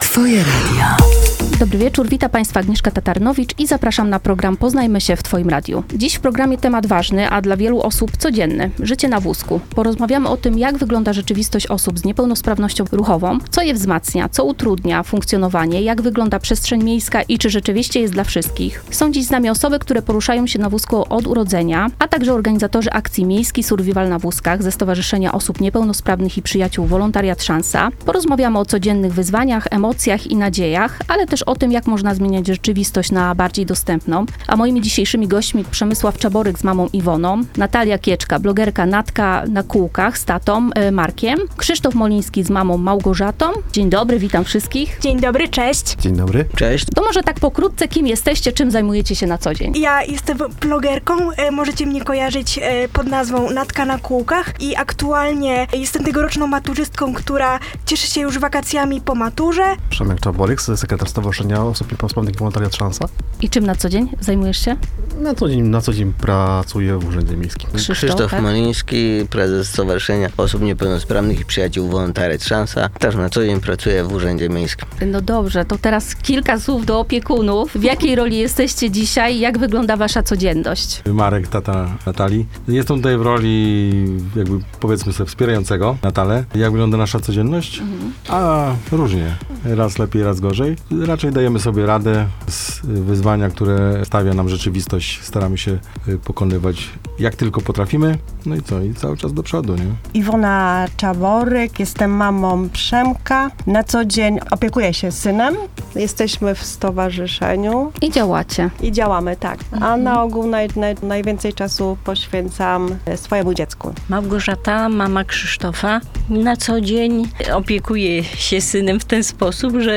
W studiu gościmy osoby poruszające się na wózkach